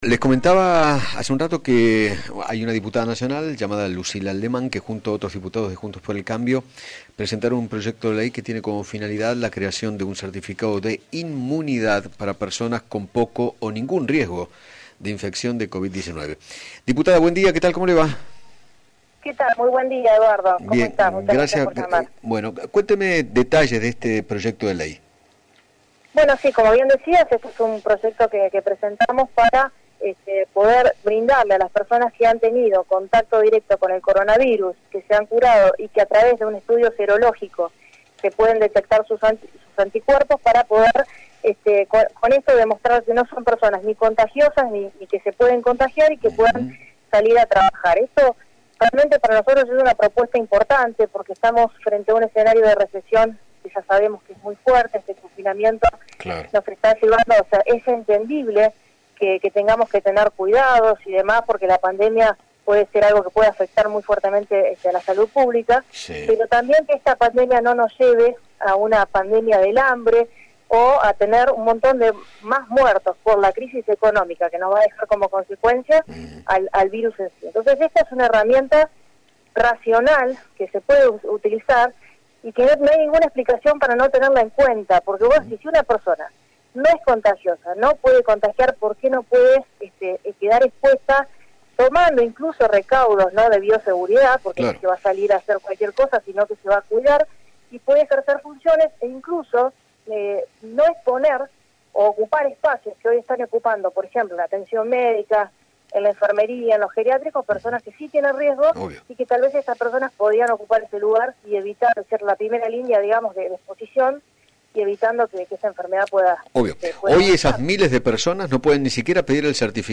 Lucila Lehman, diputada Nacional por la Coalición Cívica, dialogó con Eduardo Feinmann sobre el proyecto de ley que presentó junto a otros diputados de Juntos por el Cambio para que aquellas personas que no presentan peligro de infección de Coronavirus puedan circular libremente con los cuidados correspondientes.